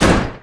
IMPACT_Generic_01_mono.wav